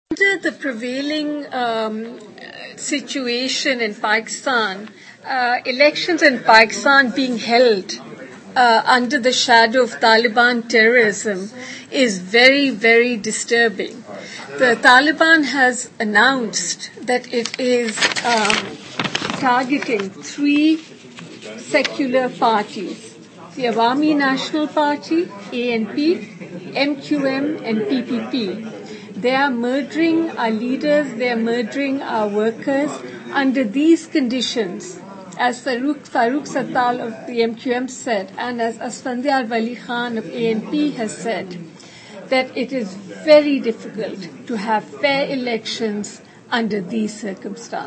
دغونډې په پایې کې ما د میرمن اصفهاني نه تپوس وکړو - د طالبانو په تواتر سره په کیڼ اړخو د حملو او سخت دریزه سیاسی ګوندونو ته د خالي میدان په نتیجه کې جوړیدونکي حکومت به سیکولر وي ؟